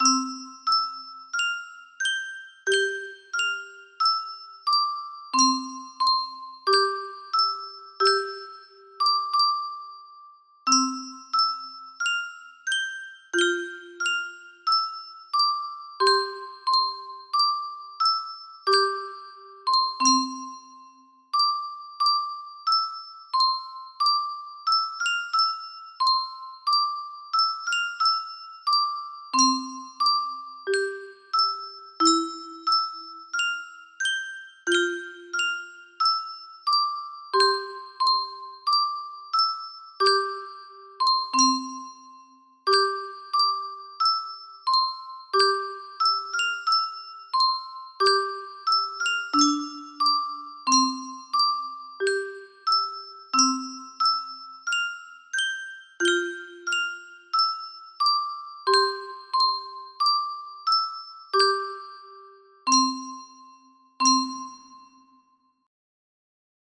Himno a la alegría (Bethoven) music box melody